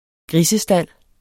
Udtale [ ˈgʁisə- ]